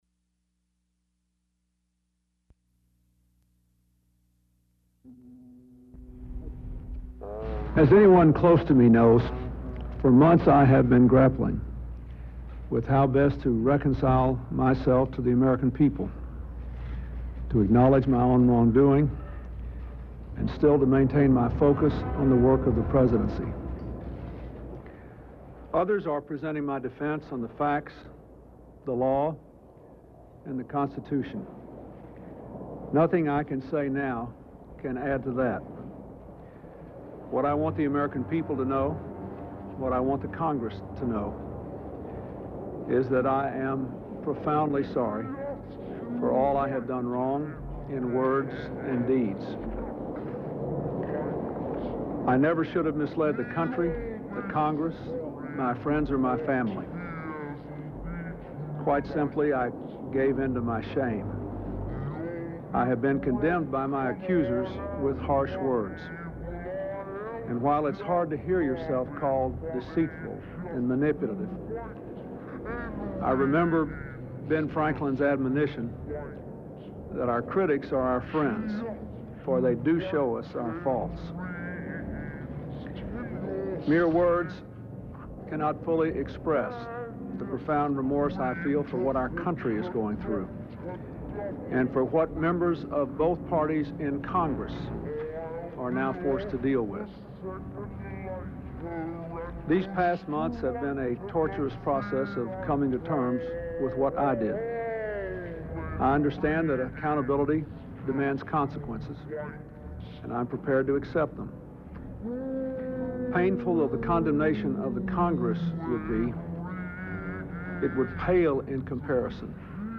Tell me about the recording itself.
Committee on the Judiciary Impeachments Trials (Impeachment) United States Material Type Sound recordings Language English Extent 00:04:00 Venue Note Broadcast on PBS-TV, News Hour, Dec. 11, 1998.